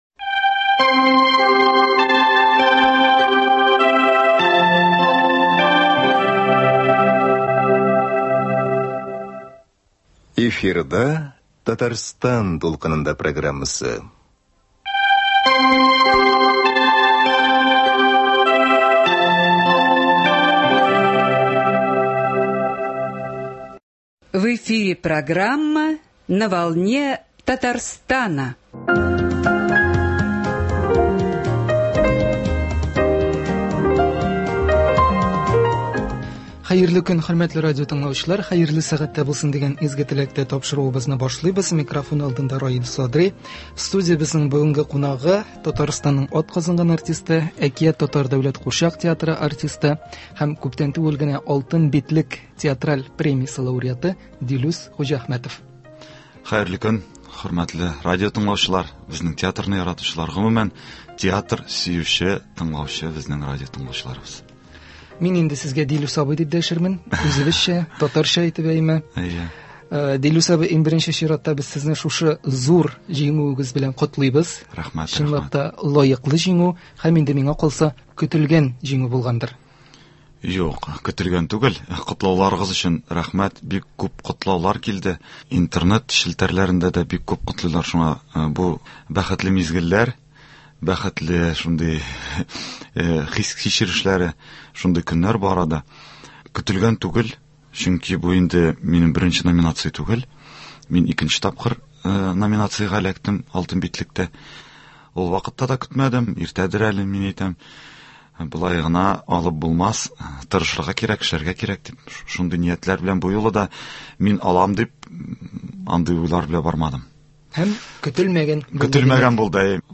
Студиябезнең бүгенге кунагы